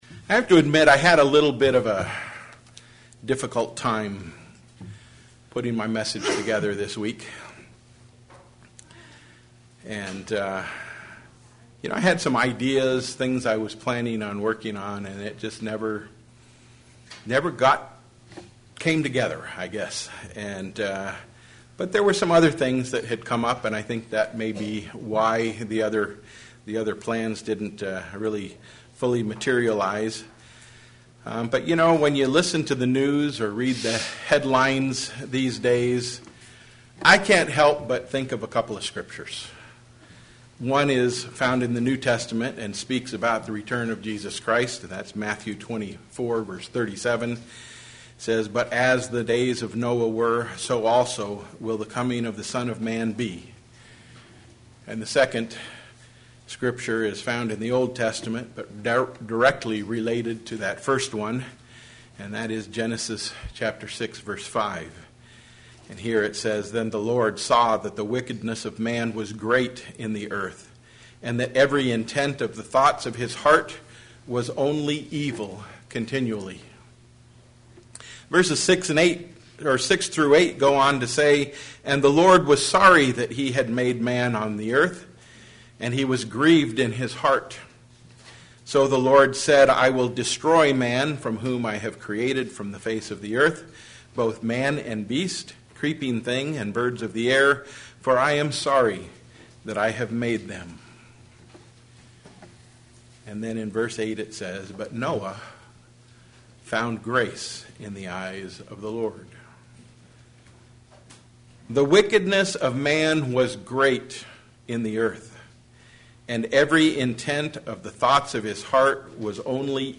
Only truth should ever pass our lips. sermon Studying the bible?